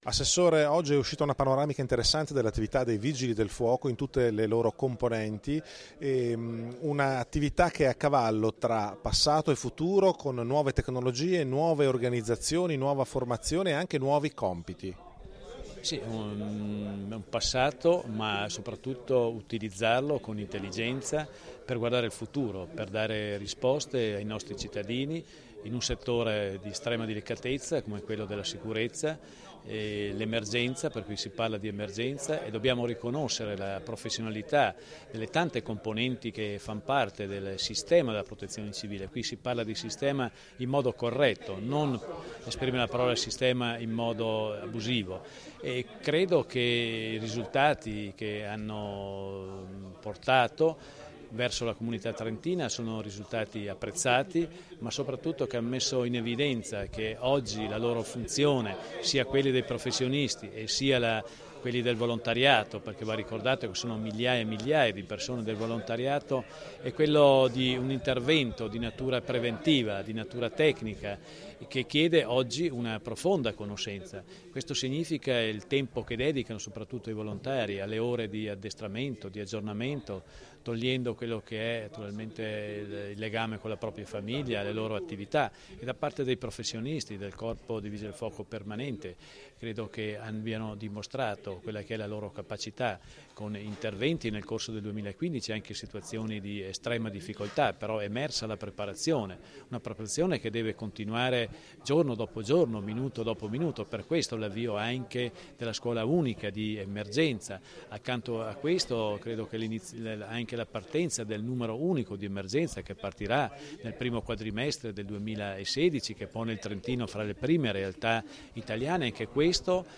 Celebrata la Patrona Santa Barbara, presso la caserma del Corpo permanente di Trento
INT_MELLARINI_SANTA_BARBARA_MP3_256K.mp3